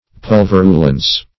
Search Result for " pulverulence" : The Collaborative International Dictionary of English v.0.48: Pulverulence \Pul*ver"u*lence\, n. The state of being pulverulent; abundance of dust or powder; dustiness.